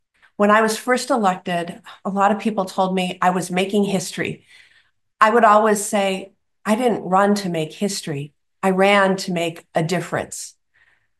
Ahead of today’s swearing in ceremony, Senator Baldwin delivered remarks via Zoom.